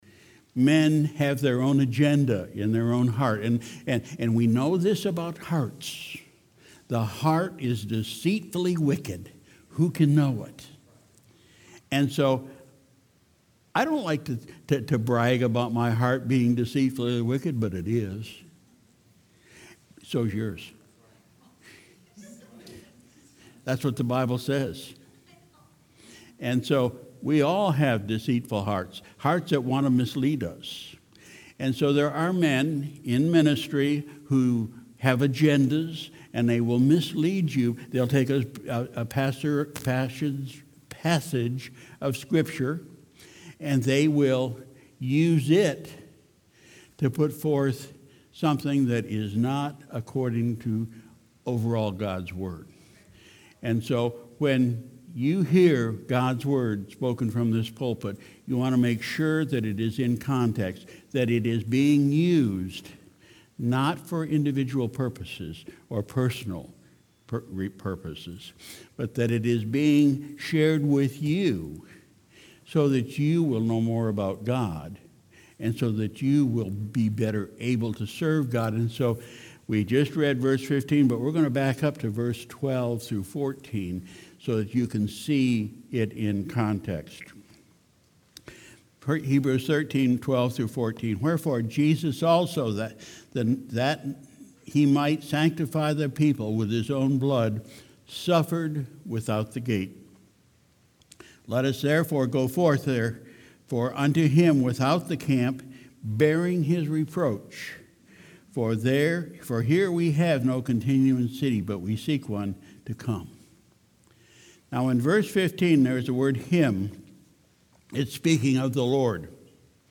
Sunday, August 18, 2019 – Evening Service